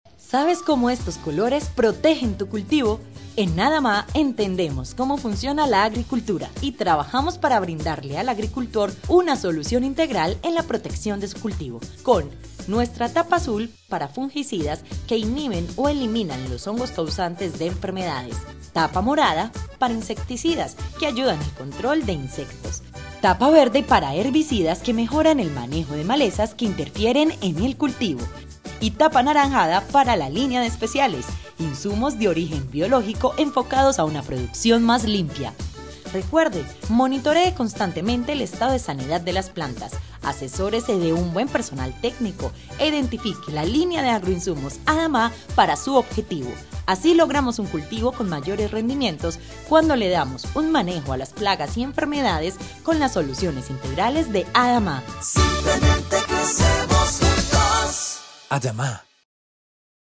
A soft voice with the kindness of a friend and the strength of a professional, from Colombia.
Sprechprobe: eLearning (Muttersprache):
I love speak with a blend between a soft and strong voice. Fresh, calm, sweet and deep when is needed.
Locución comercial_Adama.mp3